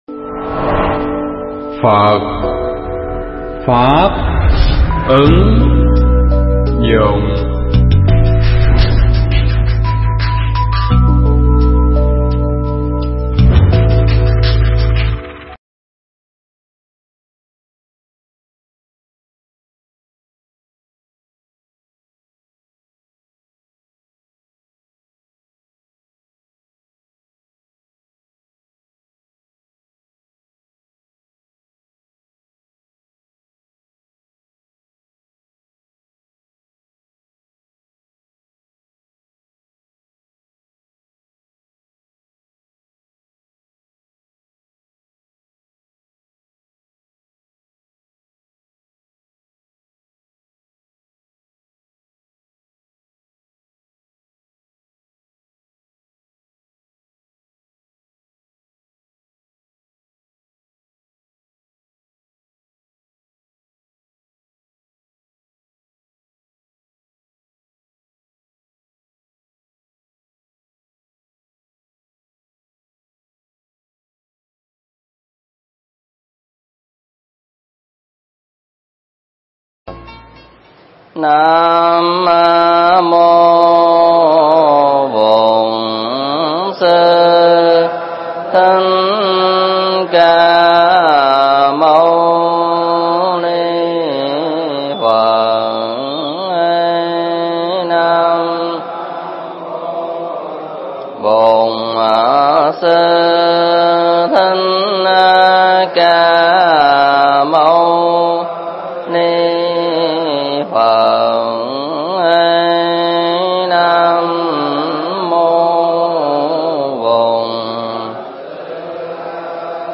Pháp thoại Kinh Viên Giác Phần 1
giảng tại tu viện Tường Vân